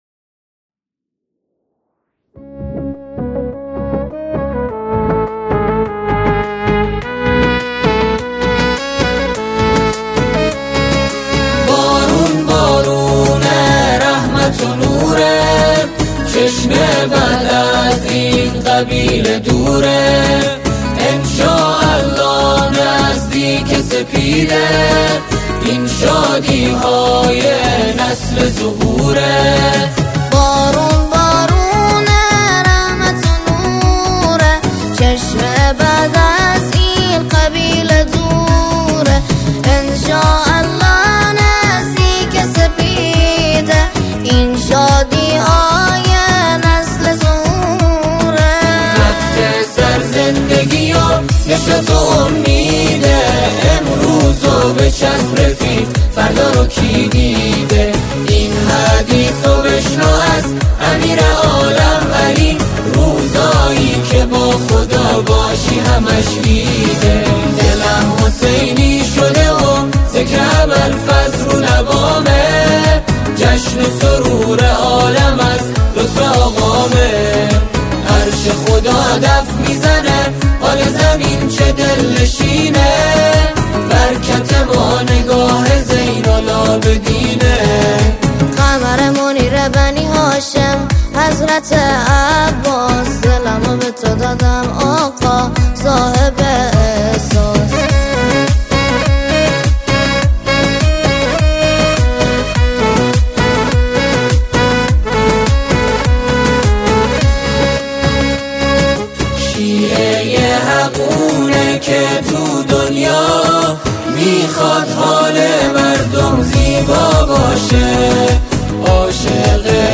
اعیاد شعبانیه